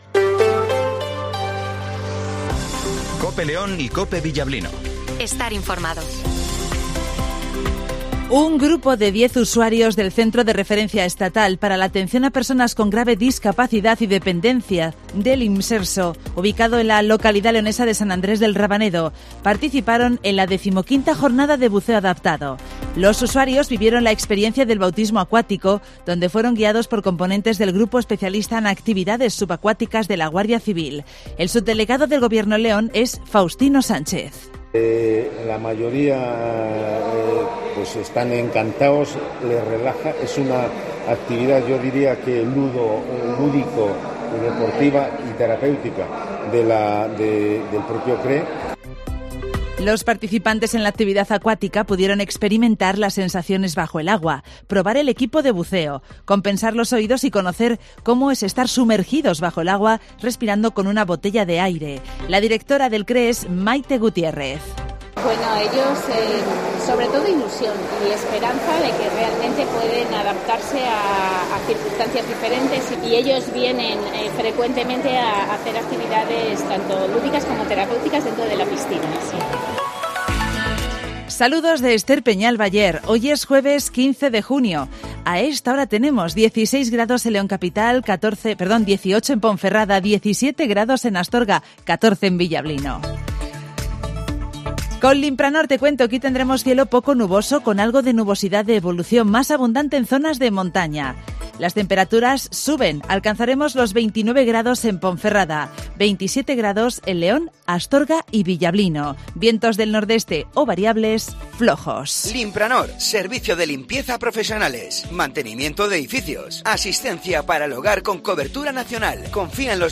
Redacción digital Madrid - Publicado el 15 jun 2023, 08:25 - Actualizado 15 jun 2023, 14:01 1 min lectura Descargar Facebook Twitter Whatsapp Telegram Enviar por email Copiar enlace - Informativo Matinal 08:20 h